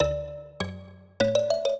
mbira
minuet0-9.wav